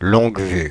Ääntäminen
France (Paris): IPA: /lɔ̃ɡ.vy/